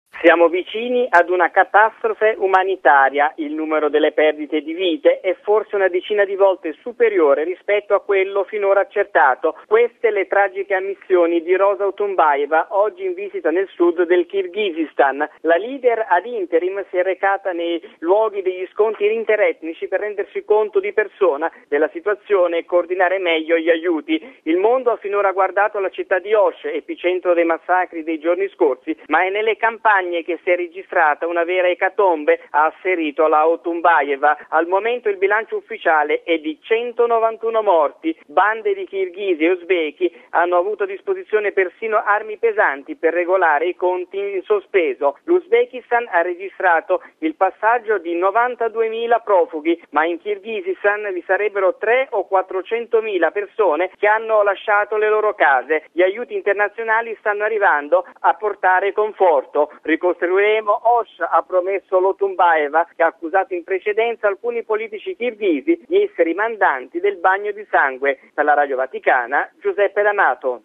La cronaca nel servizio